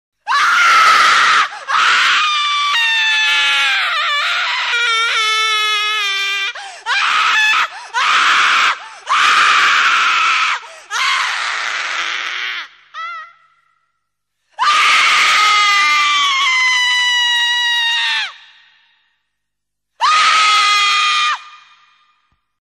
جلوه های صوتی
دانلود صدای جیغ زن ترسناک 5 از ساعد نیوز با لینک مستقیم و کیفیت بالا